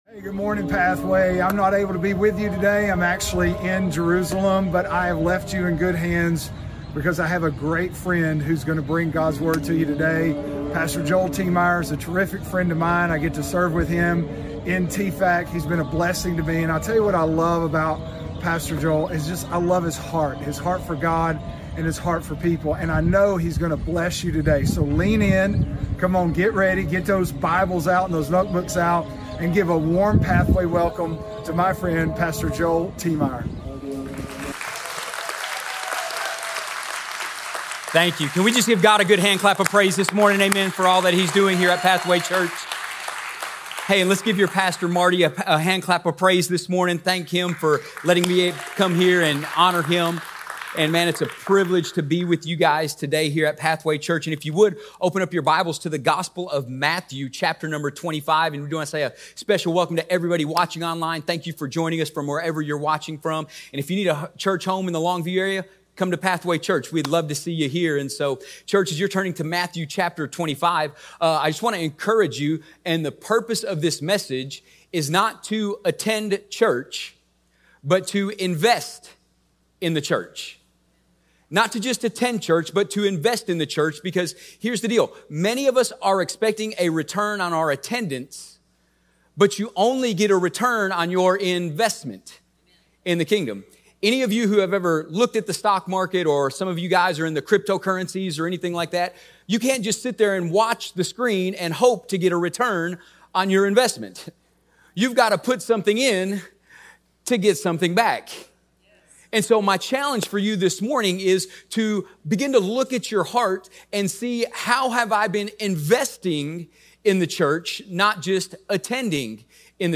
Pathway Church is located in Longview, Texas.